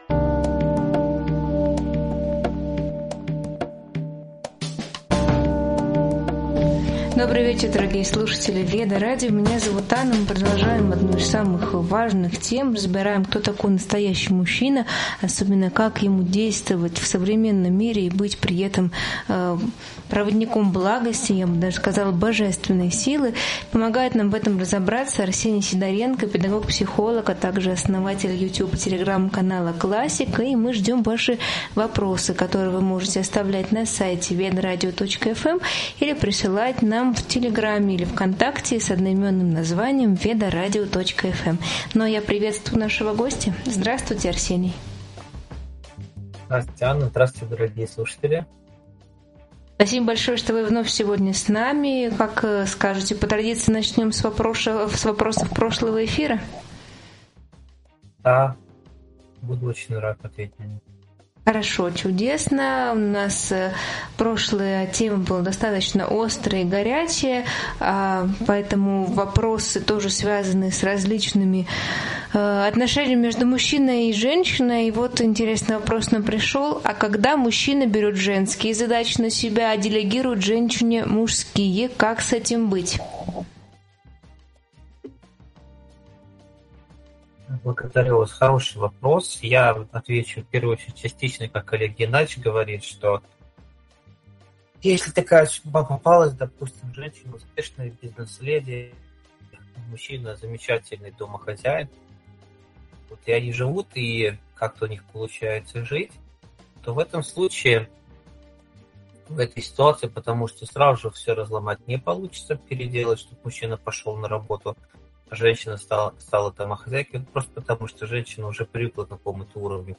Эфир посвящён теме отношений между мужчиной и женщиной и развеиванию распространённых стереотипов. Обсуждается миф о том, что мужчина разумнее женщины, и показывается, что у мужчин и женщин просто разные сильные стороны. Также рассматриваются роли партнёров, влияние мужской и женской энергии, ожидания общества и эмоциональная нагрузка в семье.